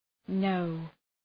Προφορά
{nəʋ}